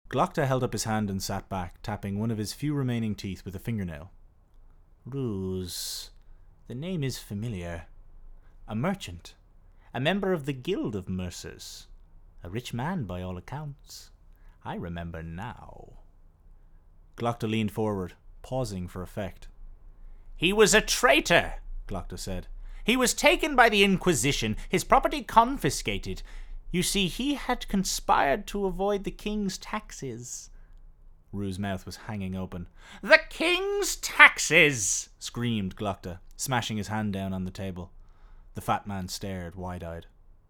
20s-30s. Male. Studio. Irish.